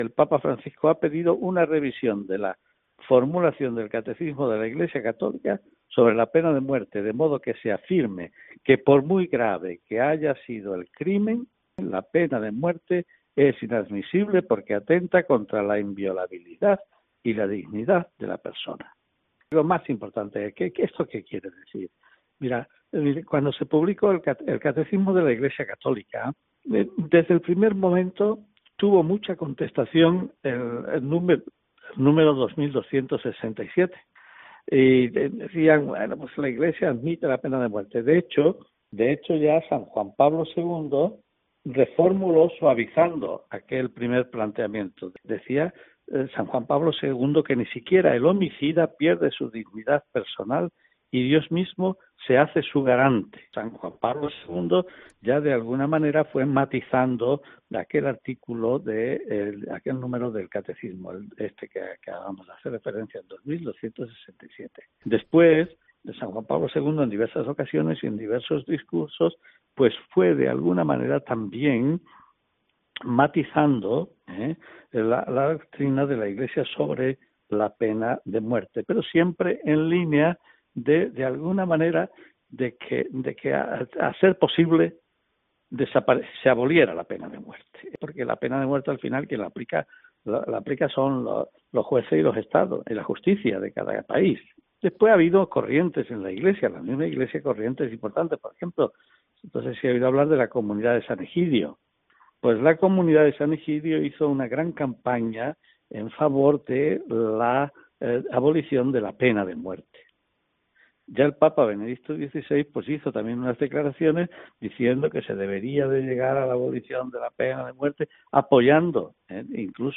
Entrevista a monseñor Amadeo, Obispo de Jaén